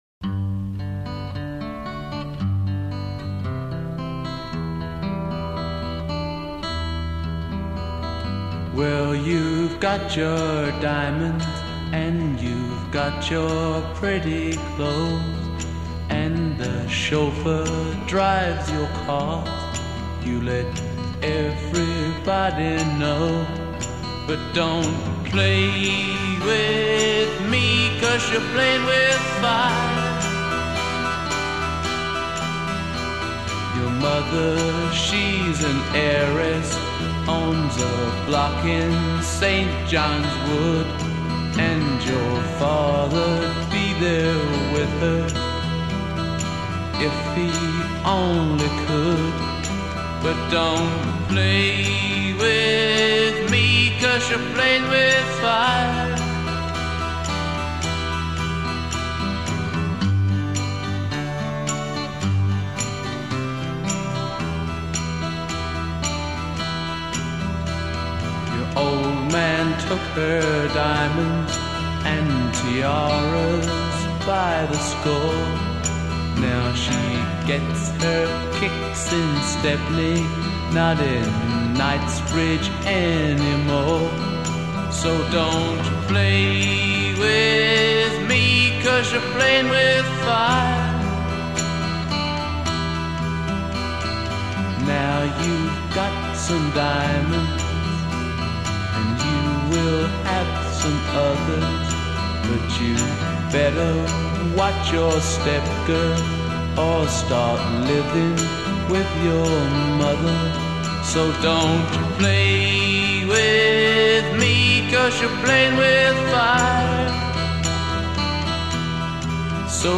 Recorded January-February 1965, RCA Studios, Hollywood.
vocals & tambourine
acoustic guitar
harpsichord
Introduction 0:00   acoustic guitar
A verse 0:   add single voice (w/ faint tambourine) a
refrain :   add harpsichord and second guitar b
refrain :   repeat refrain music without vocal
refrain     add bongo at very end b